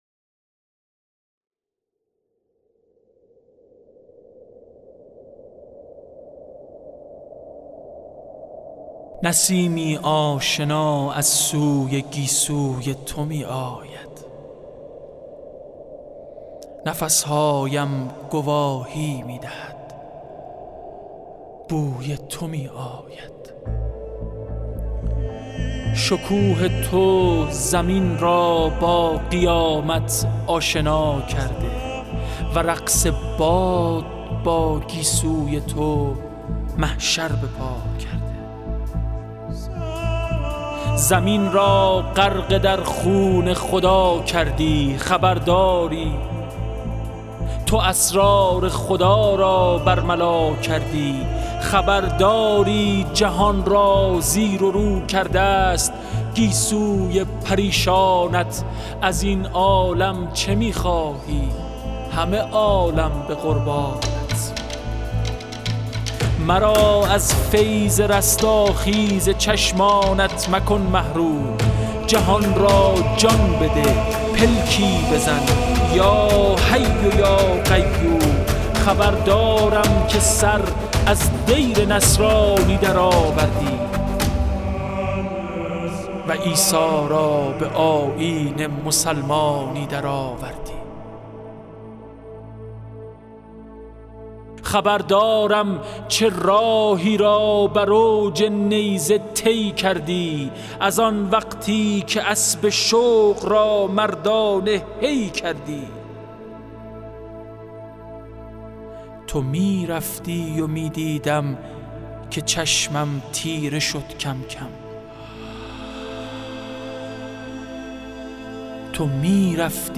شعرخوانی زیبای حمیدرضا برقعی به مناسبت اربعین حسینی صوت - تسنیم
صوت شعرخوانی زیبای سید حمیدرضا برقعی به مناسبت اربعین حسینی منتشر می شود.